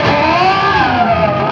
DIVEBOMB19-L.wav